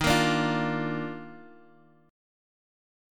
EbM7sus2 chord